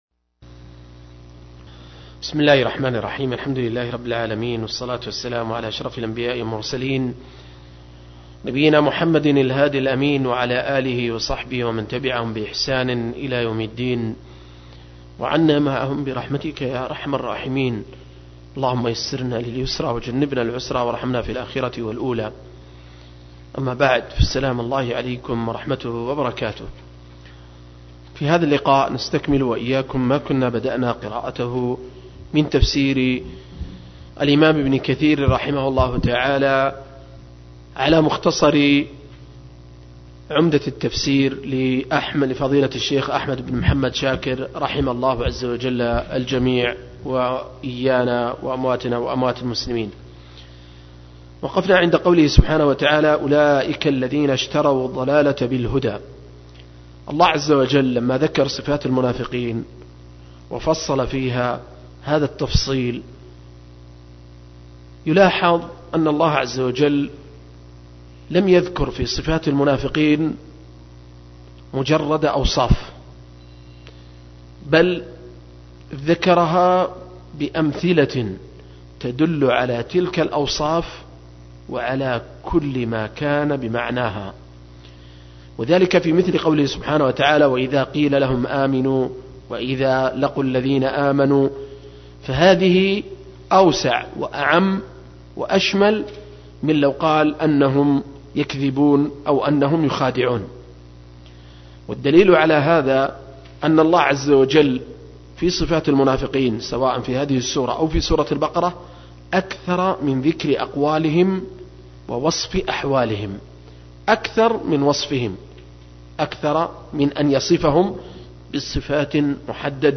010- عمدة التفسير عن الحافظ ابن كثير – قراءة وتعليق – تفسير سورة البقرة (الآيات 16-20)